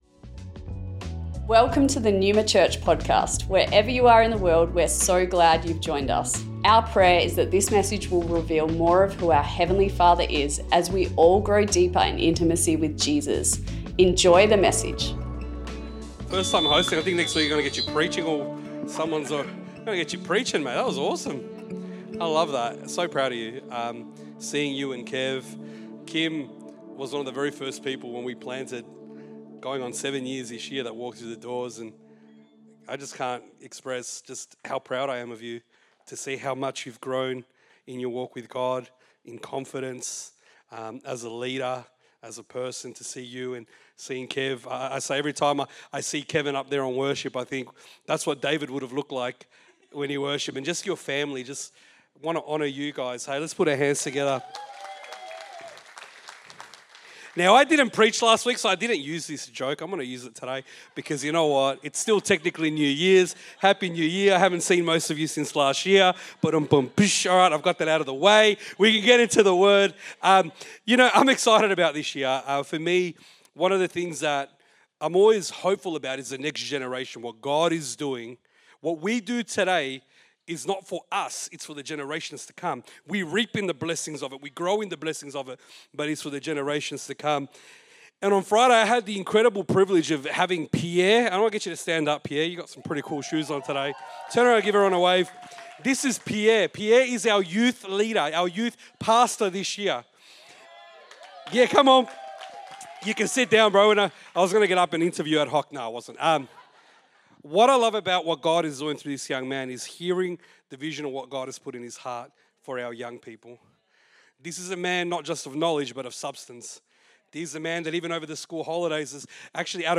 Originally recorded at Neuma Melbourne West